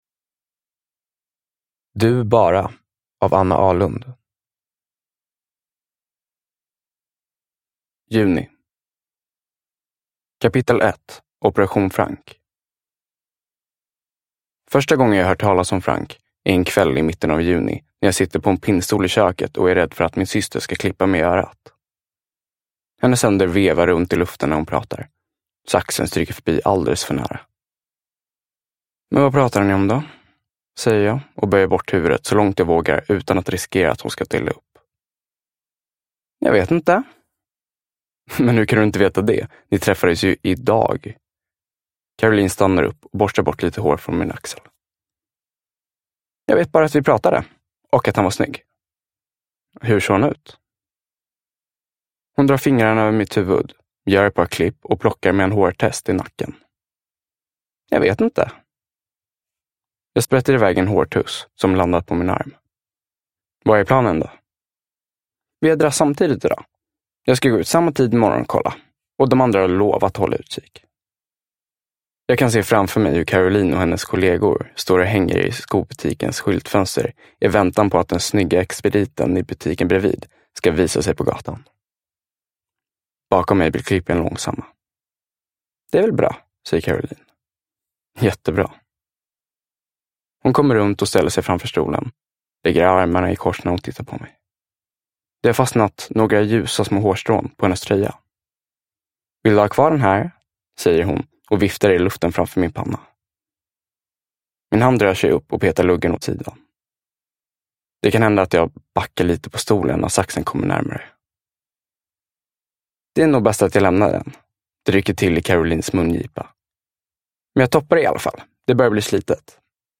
Du, bara – Ljudbok – Laddas ner